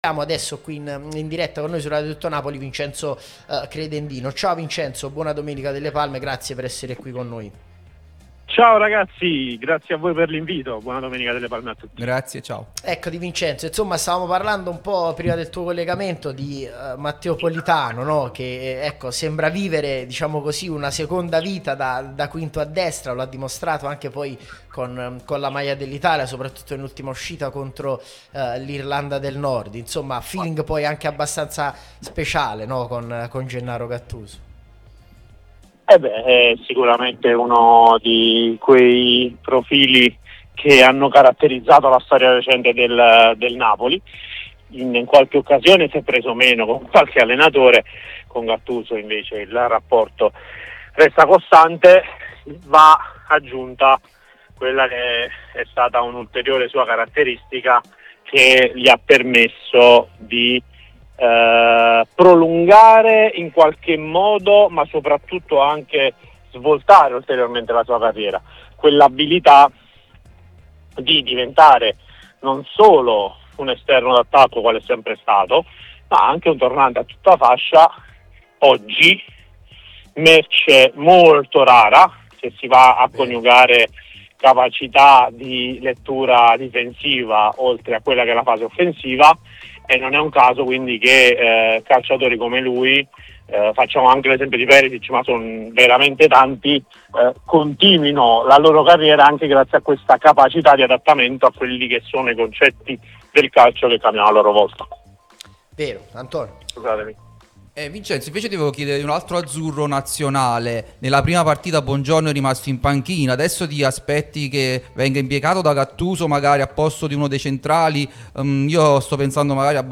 Podcast Sportitalia